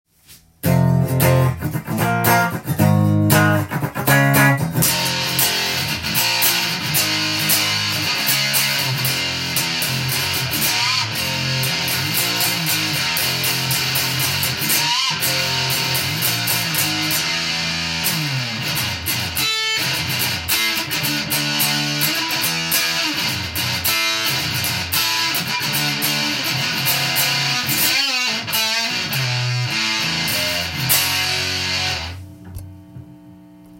ファズは、歪み系で最高のゲインを稼げるため強烈で個性があります。
試しに弾いてみました
この潰れたジャリジャリした音は、カートコバーン！
ジミヘンドリックスもこのような音です。
big.muff_.m4a